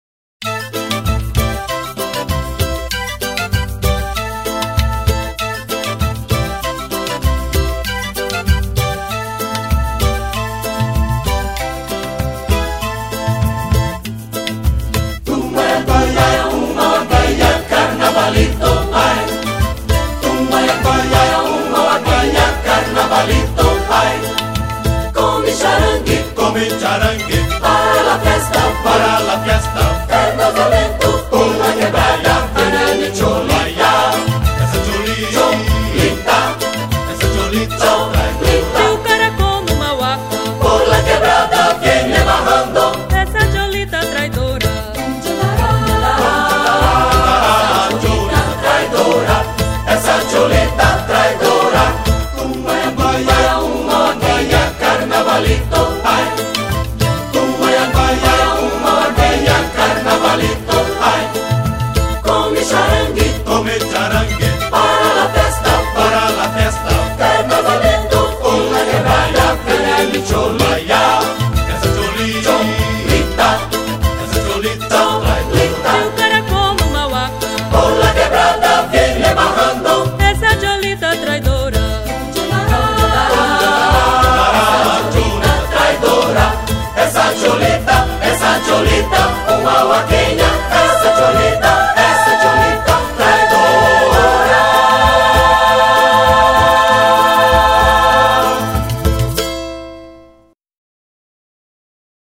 164      Faixa: 2    Rumba